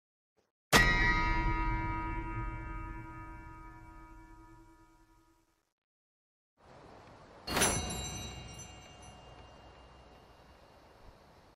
Low and high honor sfx sound effects free download
Low and high honor sfx from RDR2